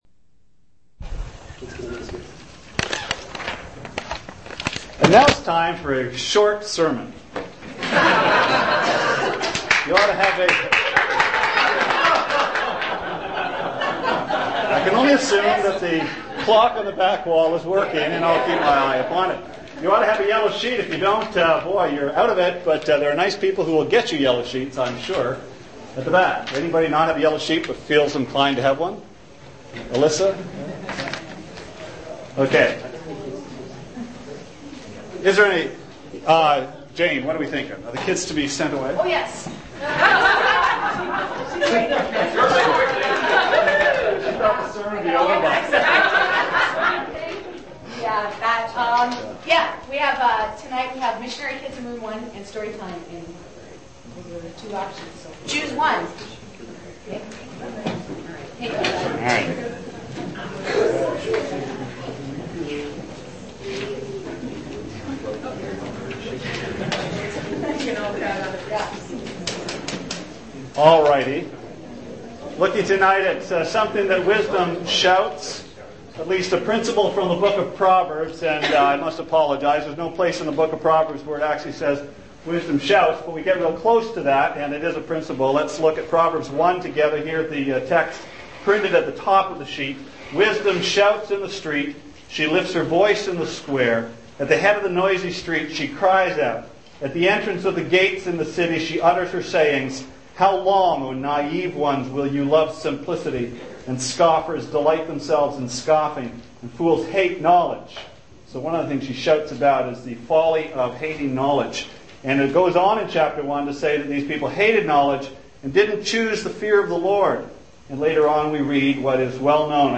Sermon Archives - West London Alliance Church
Part 6 of a Sunday evening series on Proverbs.